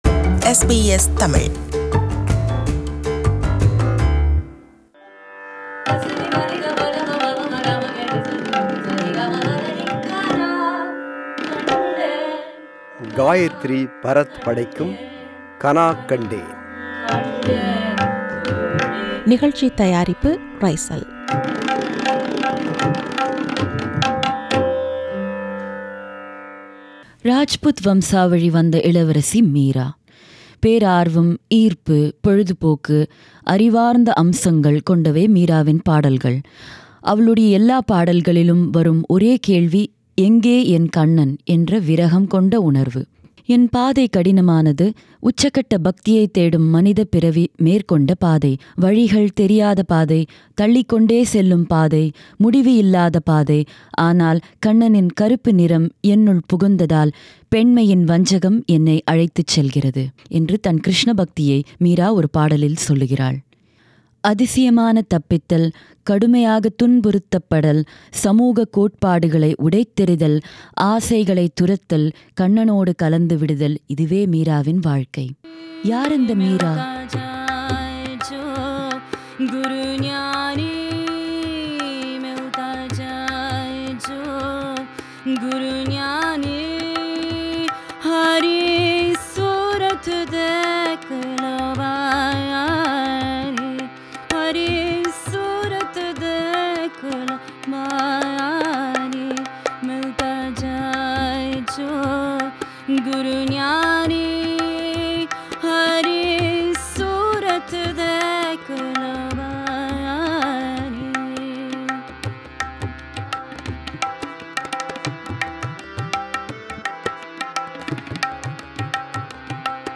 தெய்வீகத்தை, மறைபொருளைத் தேடிய ஒன்பது பெண்களின் வாழ்க்கையையும், அவர்களின் பாடல்களையும் (mystic women) பாடி, விவரிக்கும் தொடர் இது.
Harmonium
Tabla
Mridangam
Tanpura
Studio: SBS